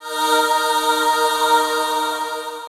Key-choir-191.1.1.wav